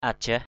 /a-ʥaɦ/ (d.) con giông = Physignatus cochinchinensis. medium lizard. nao caoh ajah _n< _c<H ajH đi đào bắt giông.